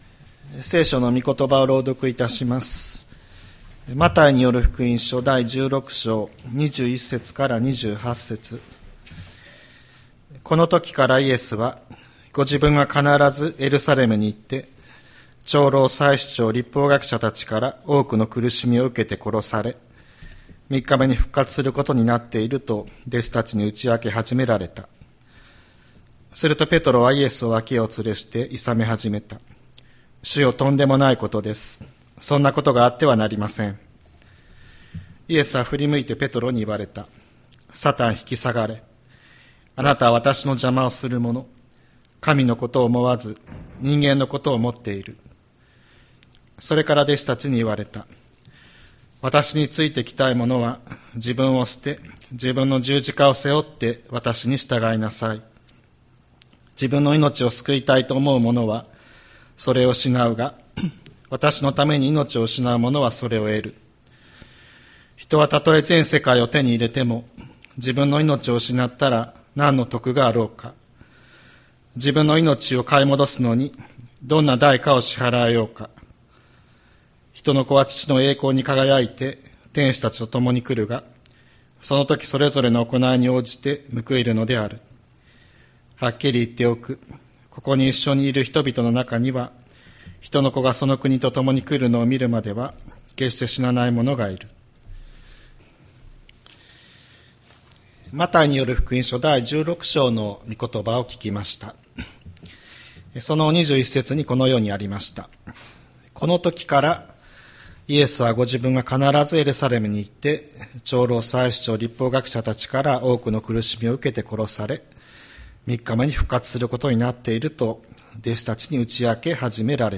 千里山教会 2025年11月16日の礼拝メッセージ。